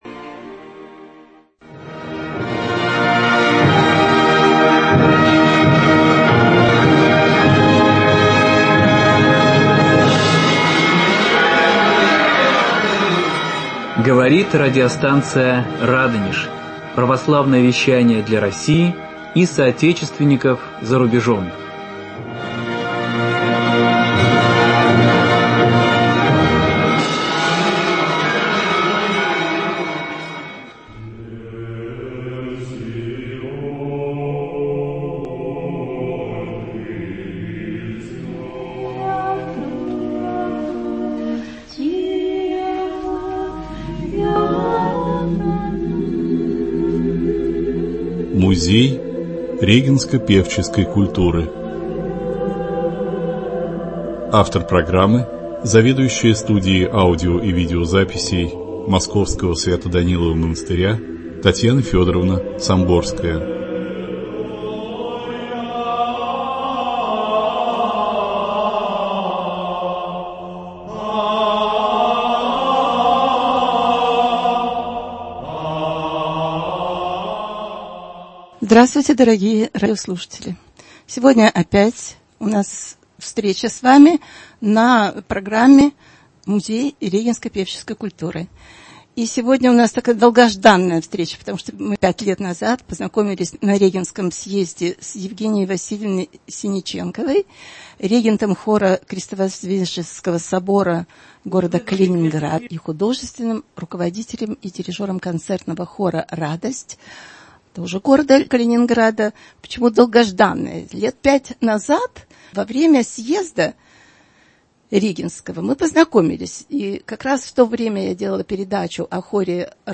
Говорим с ней и о композиторе Шведове, а также о духовной музыкальной жизни в Калининградской епархии и наследовании православной традиции молодым поколением музыкантов. Звучит много редких музыкальных фрагментов.